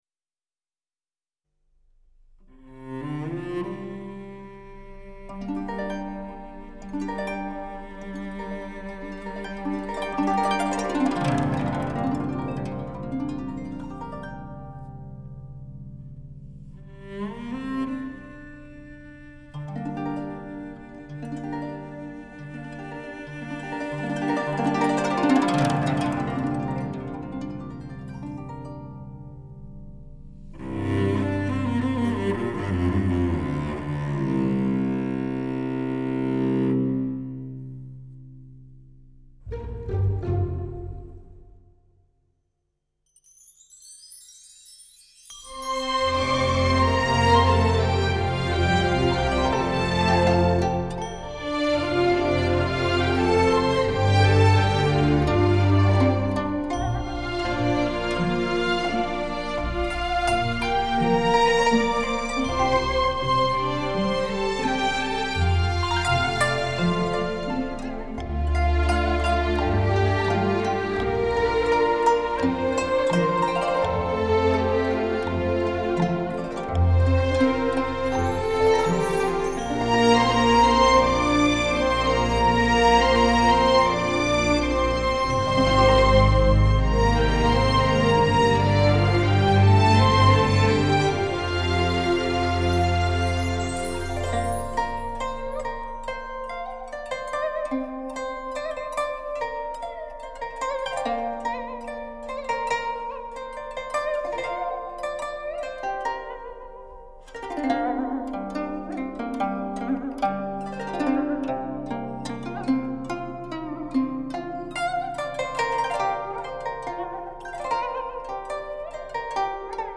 古筝玲珑二胡洒脱，碰盅木鱼灵秀点染，机巧对答意兴盎然。